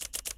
2. camera continuous shooting